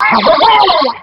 Line of Bubbler in Diddy Kong Racing.